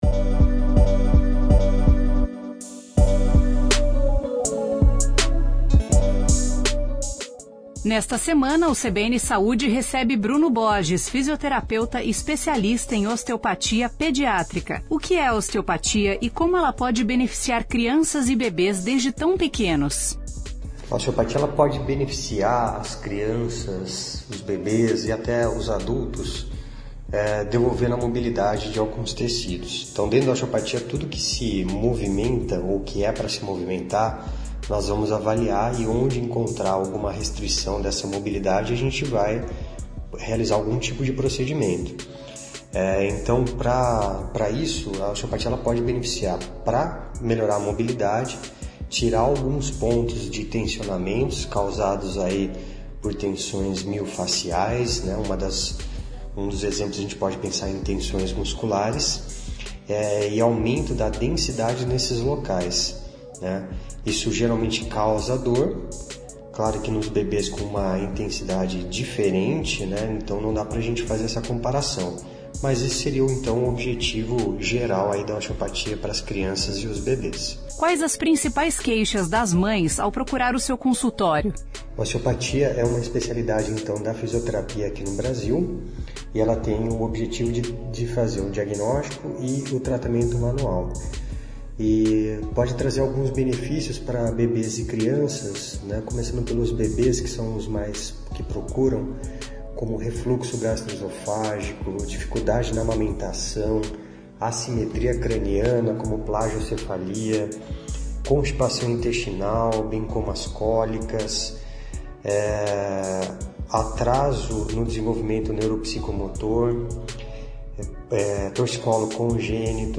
fisioterapeuta especialista em osteopatia pediátrica. A área estuda a saúde das crianças, incluindo as principais disfunções e sintomas dos bebês, como cólicas, refluxos e insônias.